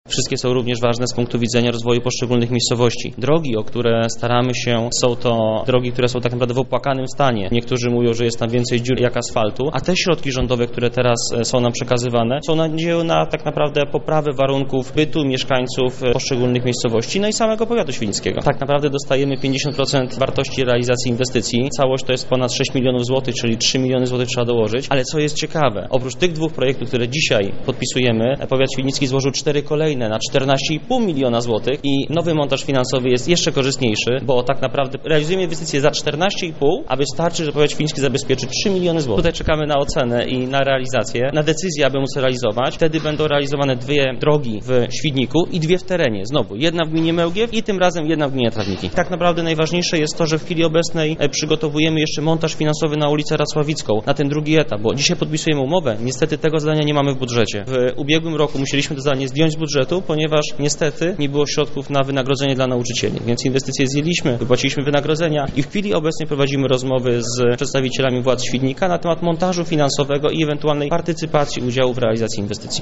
Wszystkie inwestycje drogowe są ważne dla mieszkańców powiatu świdnickiego- tłumaczy starosta świdnicki Łukasz Reszka
starosta.mp3